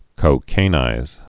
(kō-kānīz)